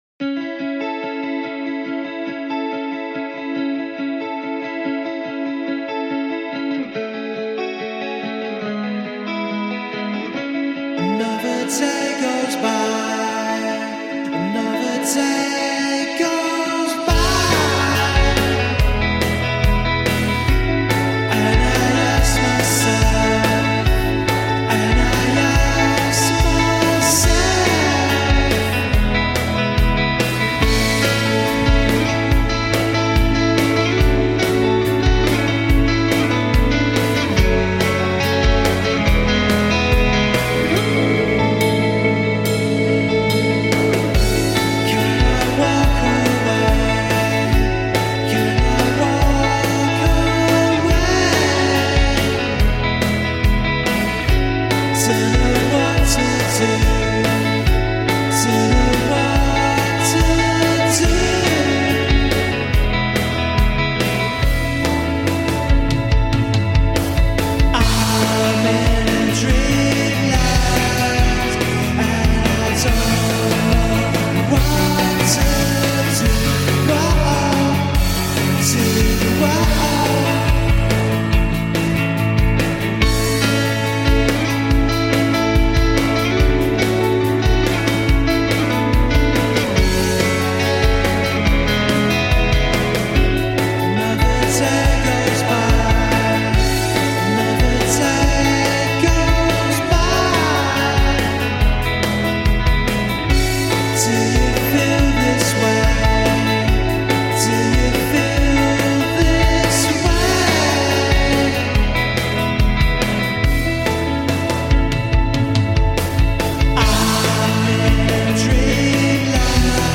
Jangly London UK dream pop quartet